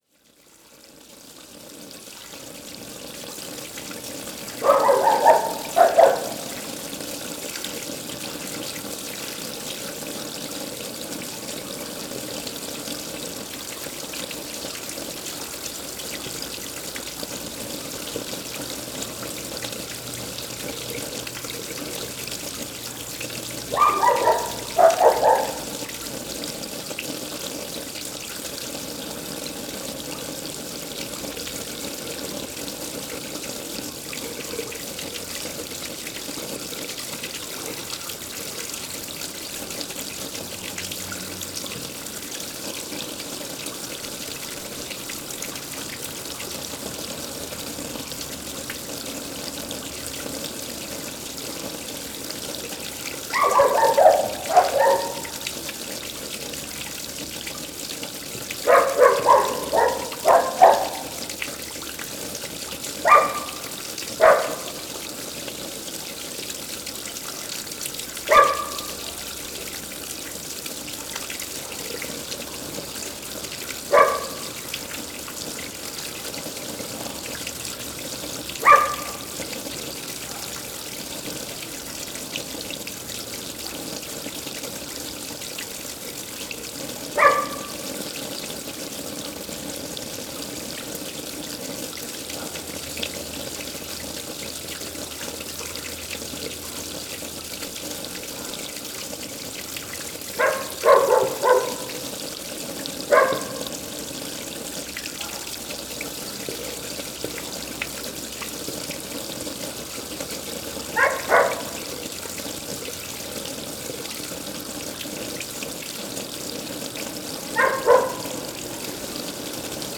Paisagem sonora de escoamento de água de lavadouro comunitário em Almargem (Outra perspectiva), Calde a 17 Fevereiro 2016.